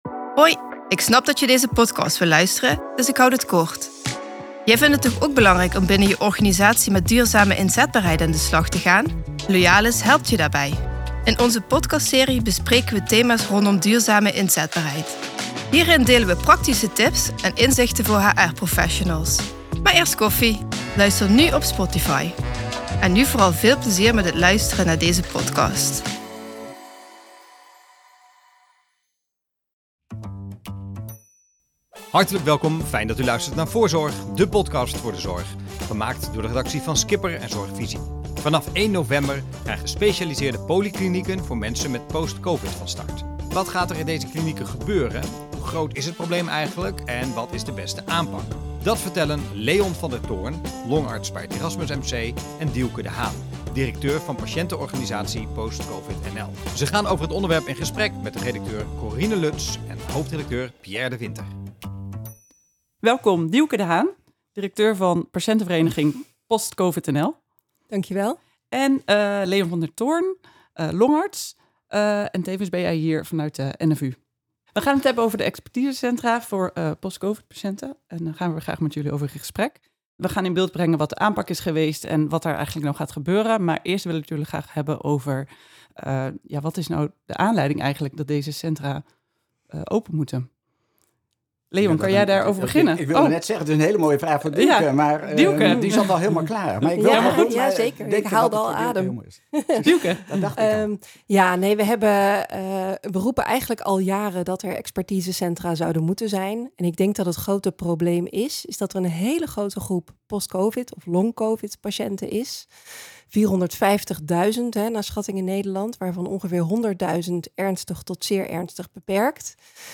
Today, we're at Van Der Valk Restaurant Hotel, in Eindhoven and we go international!